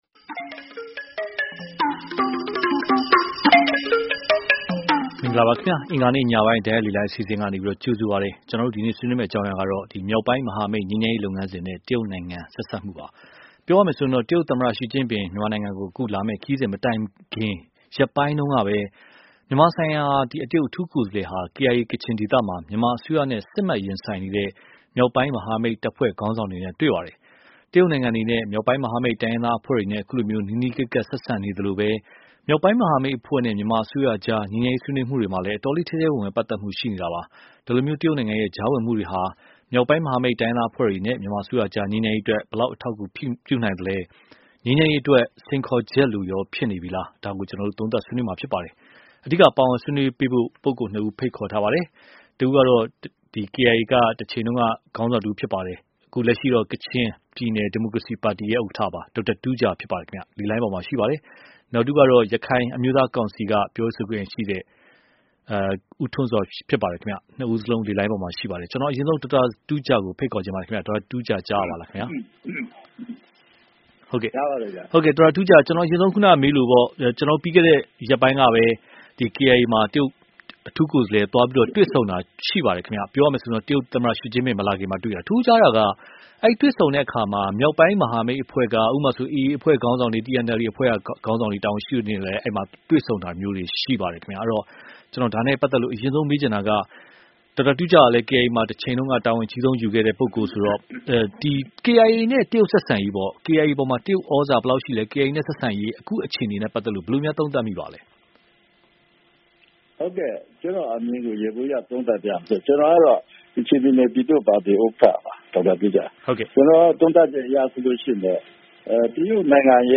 မြောက်ပိုင်းမဟာမိတ်ငြိမ်းချမ်းရေးလုပ်ငန်းစဉ်နဲ့ တရုတ်နိုင်ငံ (တိုက်ရိုက်လေလှိုင်း)